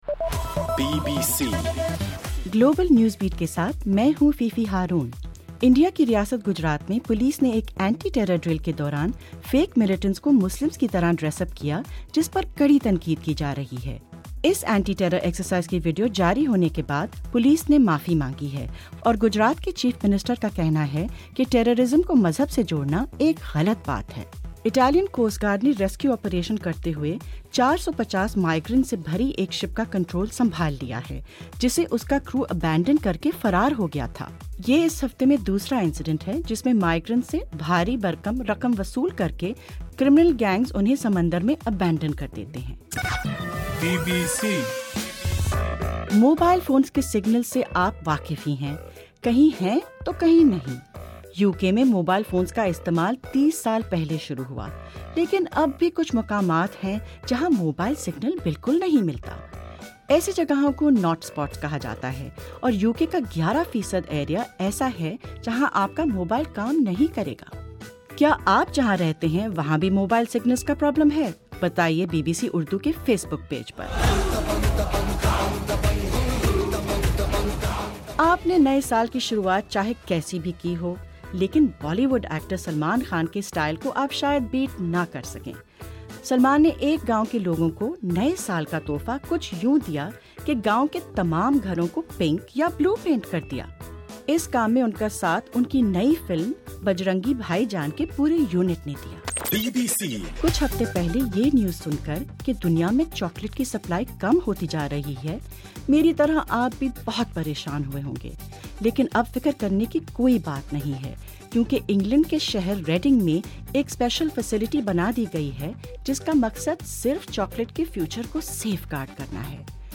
جنوری 02: رات 8 بجے کا گلوبل نیوز بیٹ بُلیٹن